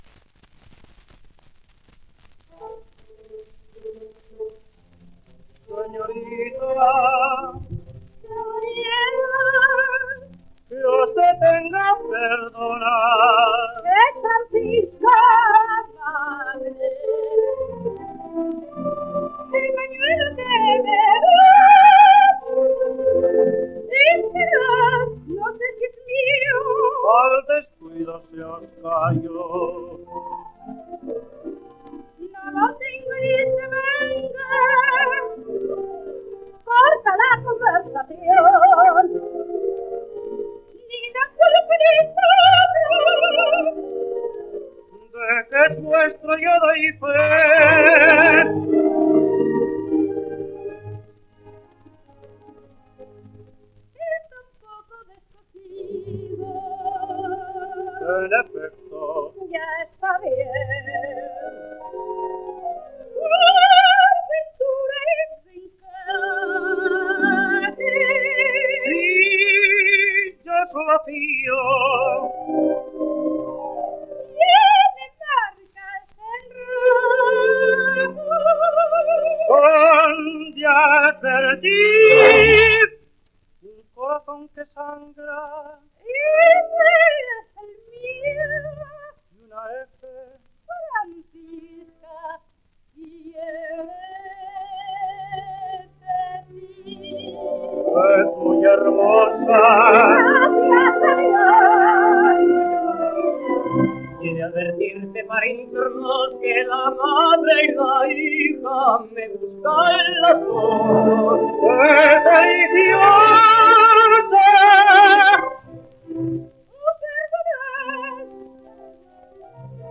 Doña Francisquita. Preludio, parte 2. (sonido mejorado)
Editado por: Regal 8 discos : 78 rpm ; 30 cm